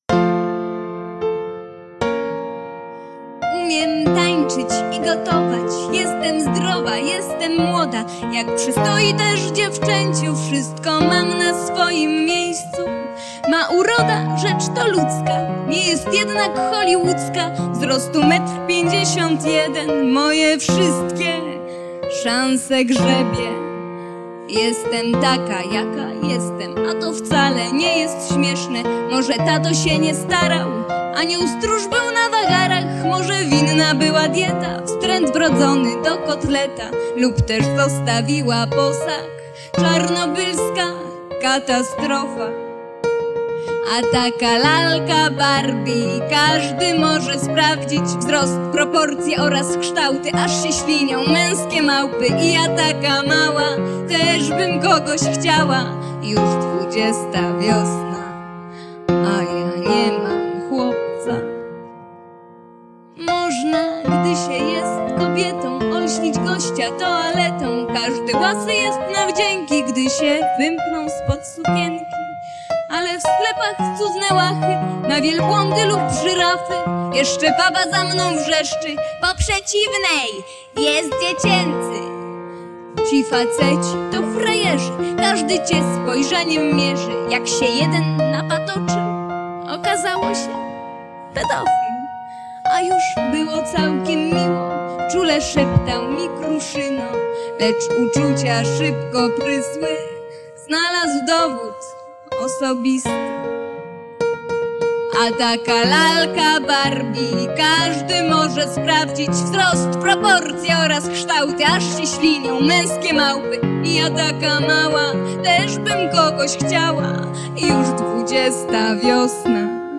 Sądząc po piosenkach był to konkurs o odcieniu „kabaretowym”.
Problem z tą kasetą miałem jednak taki, że nagrania na niej są słabej jakości, tak słabej na „grubo”. Postawiony jakiś zwykły magnetofon i na niego nagrane naraz wszystko, słaby klawisz i wokal, nawet chyba w mono.
nagranie z początku lat 90-tych na pianinie